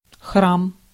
Ääntäminen
IPA: /sxrɛin/